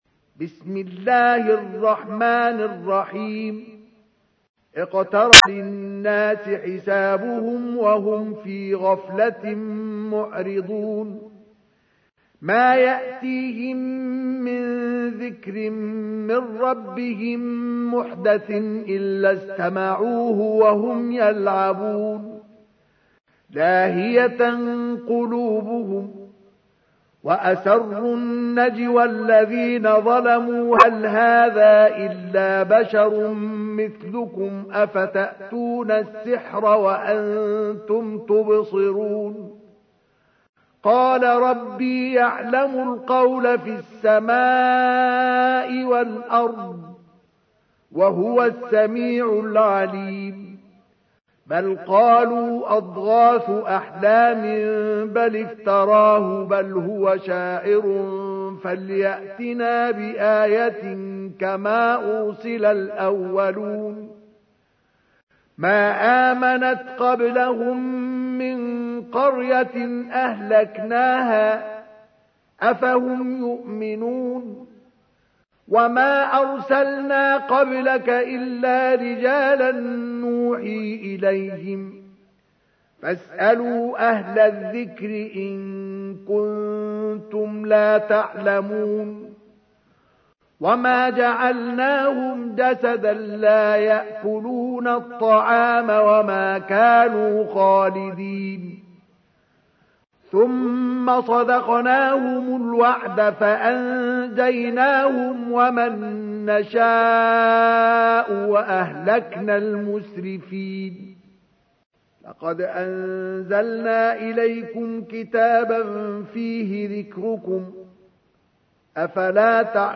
تحميل : 21. سورة الأنبياء / القارئ مصطفى اسماعيل / القرآن الكريم / موقع يا حسين